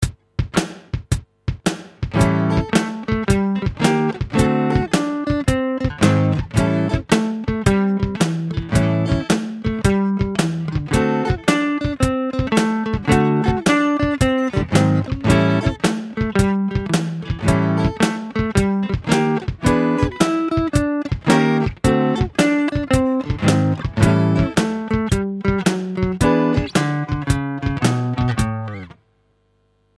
You can even try mixing the chords with the riffs to create a more full sound blues/rock rhythm guitar sound.
Blues Riff Chords | Download
blues_riff_chords.mp3